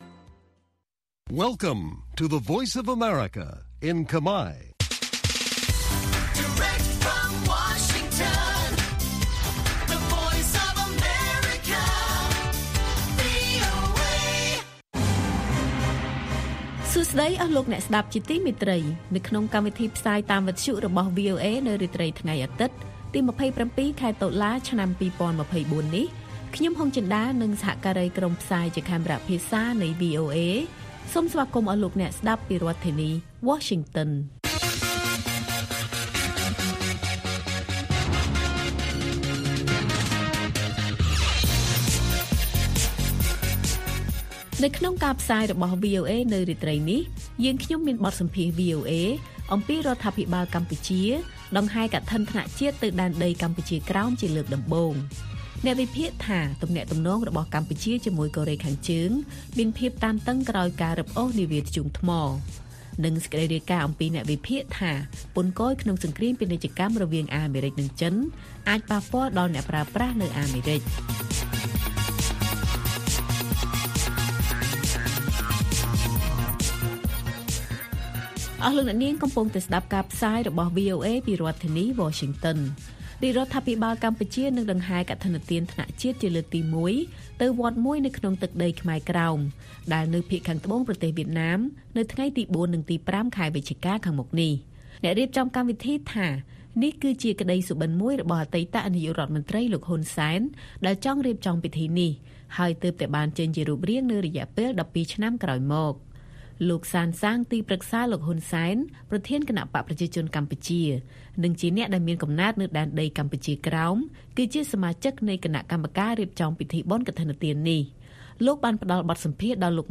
ព័ត៌មានពេលរាត្រី ២៧ តុលា៖ បទសម្ភាសន៍វីអូអេអំពីរដ្ឋាភិបាលកម្ពុជាដង្ហែកឋិនថ្នាក់ជាតិទៅដែនដីកម្ពុជាក្រោមជាលើកដំបូង